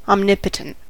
omnipotent: Wikimedia Commons US English Pronunciations
En-us-omnipotent.WAV